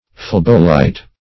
Search Result for " phlebolite" : The Collaborative International Dictionary of English v.0.48: Phlebolite \Phleb"o*lite\, Phlebolith \Phleb"o*lith\, n. [Gr.